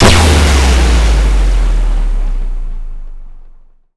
death.LN65.pc.snd.wav